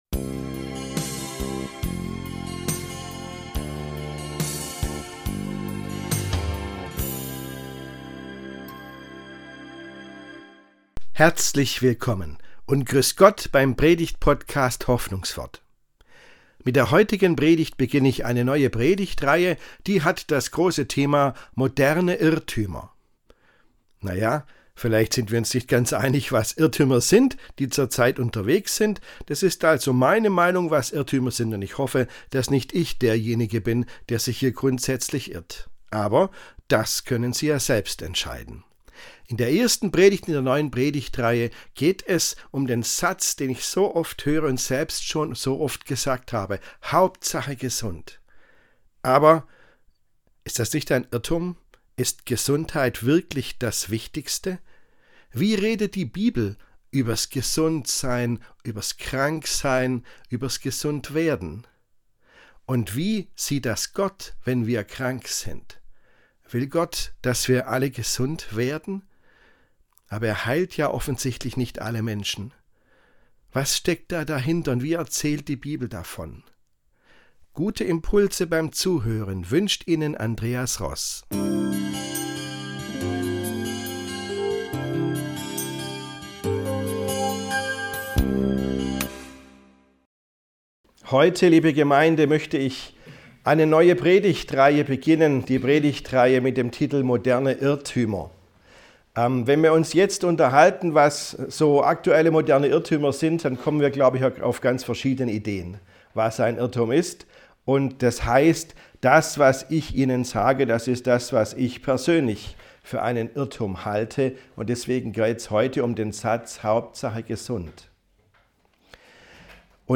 Die erste Predigt in der neuen Predigtreihe „Moderne Irrtümer“ setzt sich mit der Frage auseinander, ob Gesundheit wirklich die Hauptsache ist, wie wir immer wieder hören.